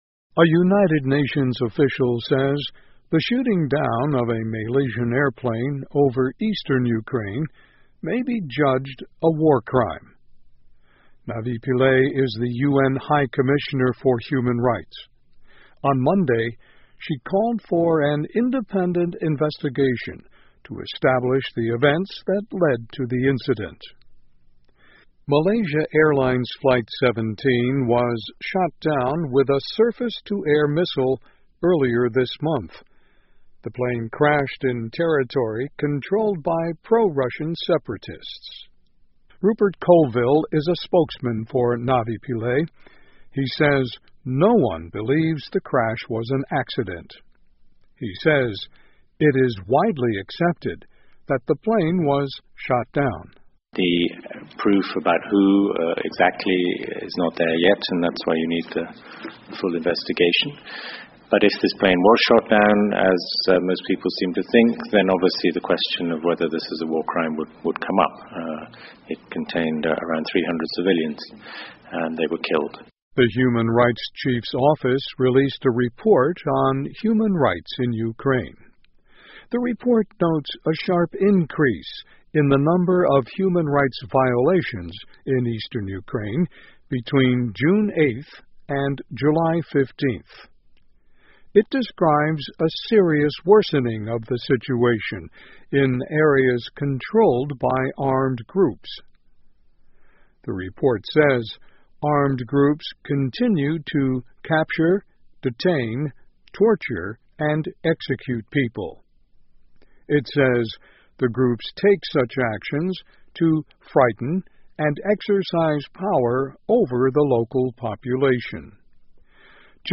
VOA慢速英语2014 联合国称击落马航客机或是“战争罪行” 听力文件下载—在线英语听力室